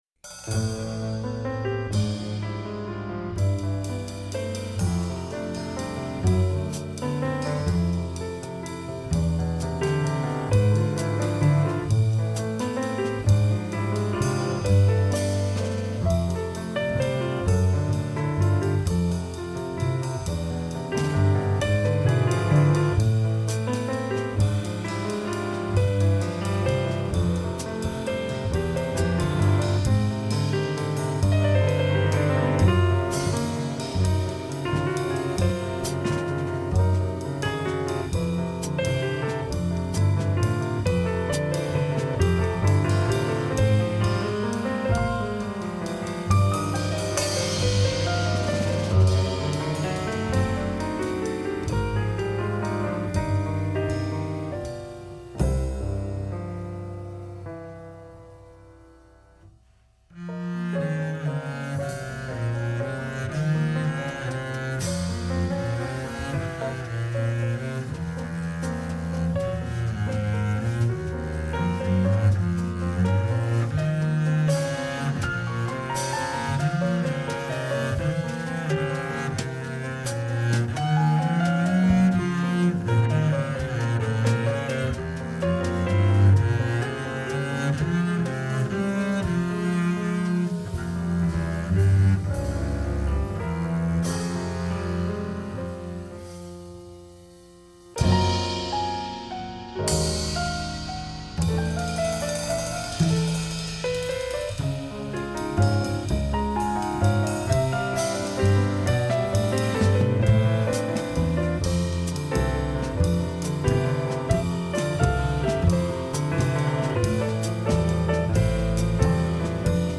Incontro con il pianista